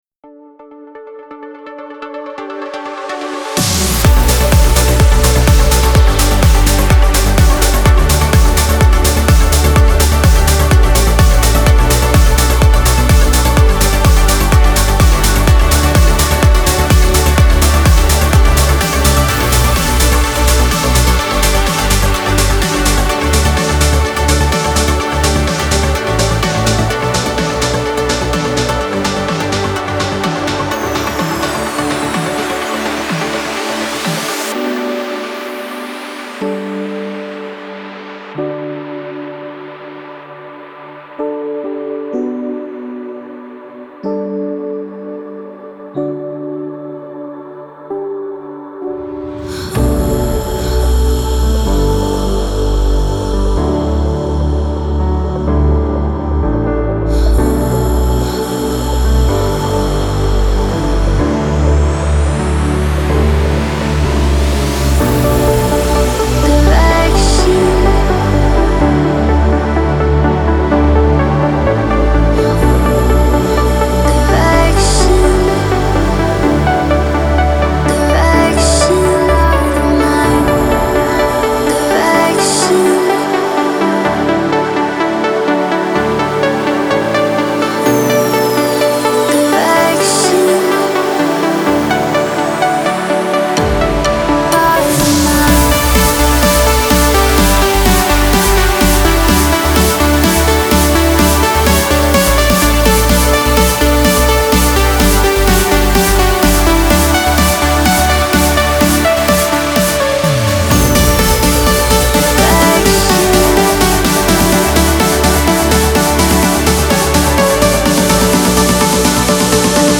ترنس
موسیقی بی کلام ورزشی
موسیقی بی کلام پر انرژی